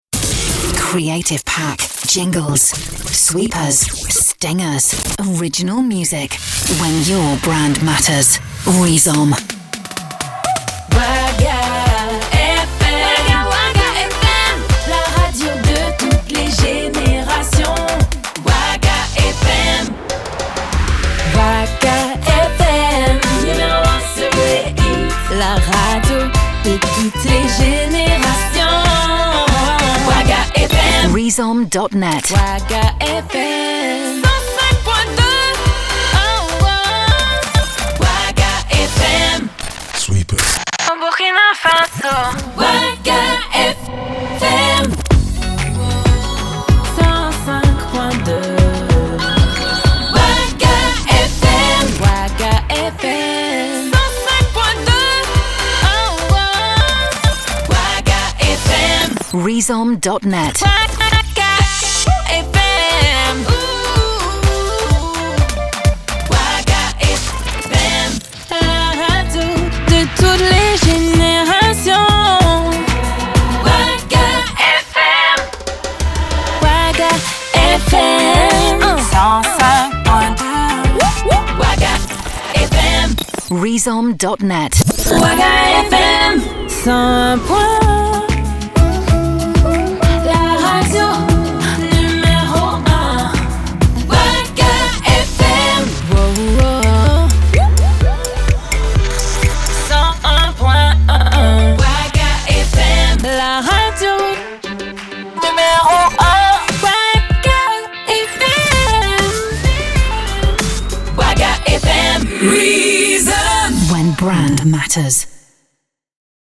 Jingles radio Afrique